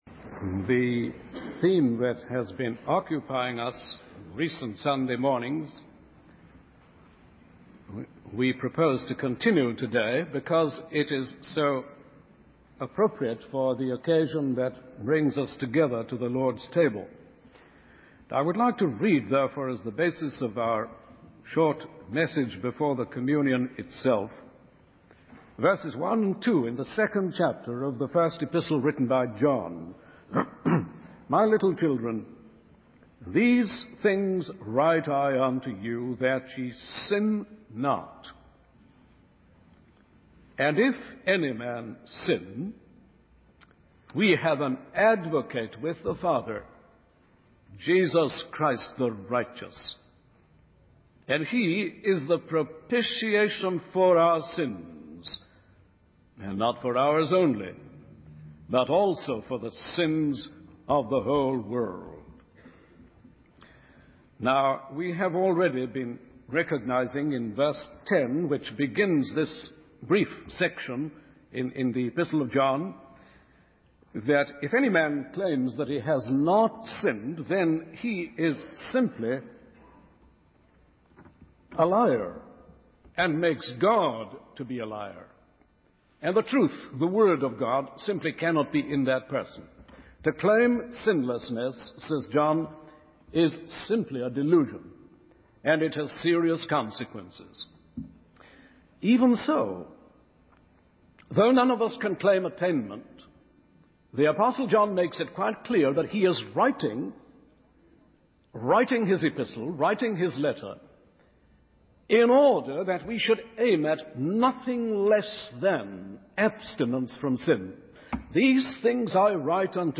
In this sermon, the preacher emphasizes the importance of having a personal relationship with God and maintaining a life free from sin. He highlights the role of Jesus as our advocate and propitiation, who intercedes for us with the Father when we fail and sin.